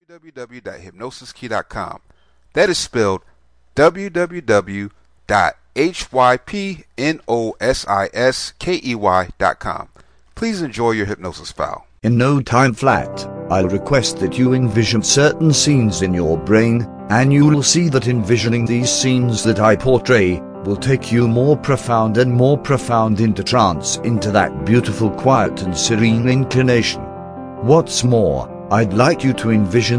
Welcome to Perfect Pianist Self Hypnosis Mp3, this is a powerful hypnosis script that helps you learn to be a good piano player.